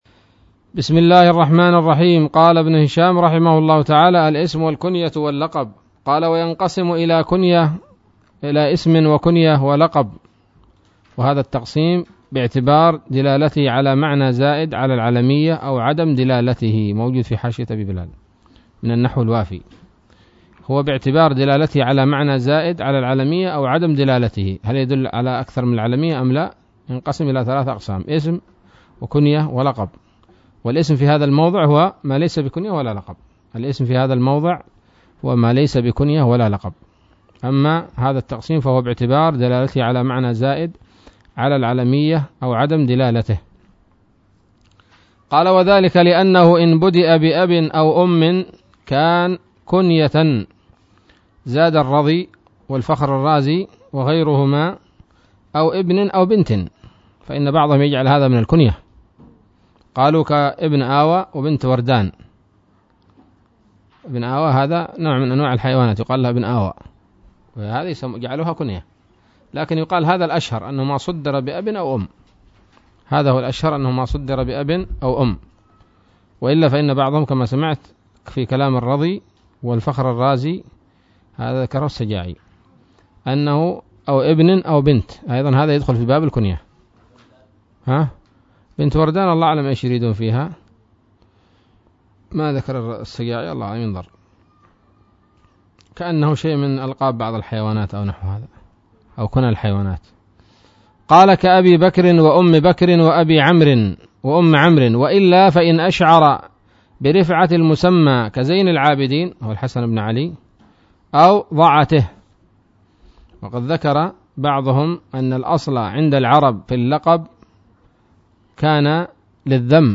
الدرس الرابع والأربعون من شرح قطر الندى وبل الصدى